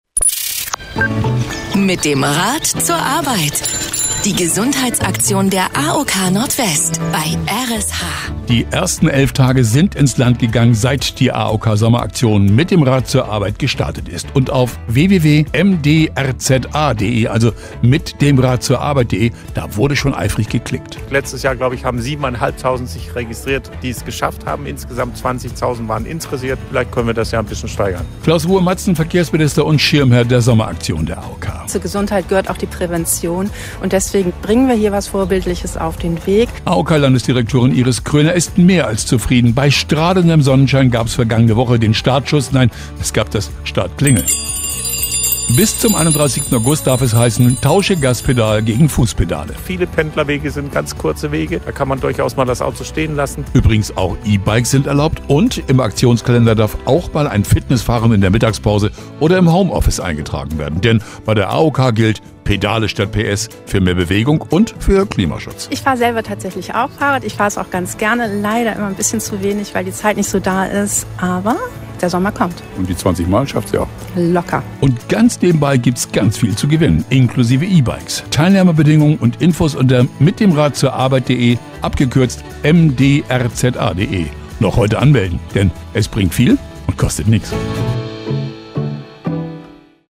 R.SH-Sendebeitrag 2